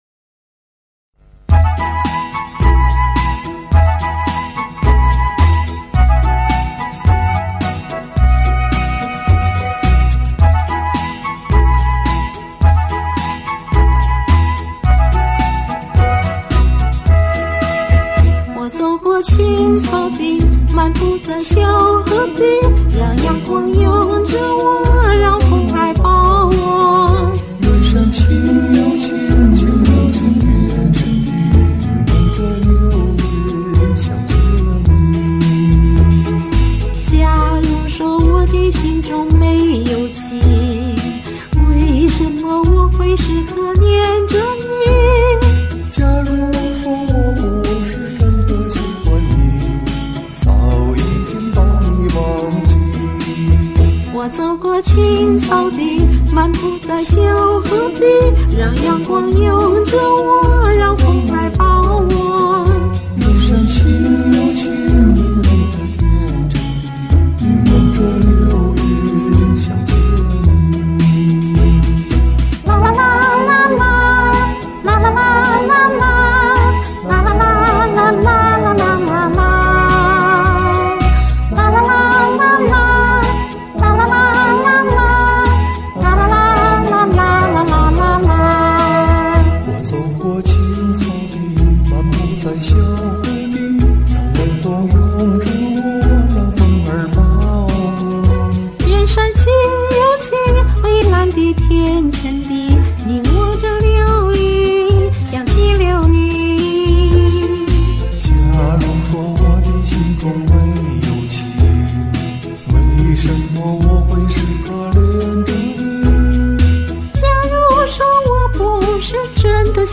：）唱得欢快阳光很有节日气氛！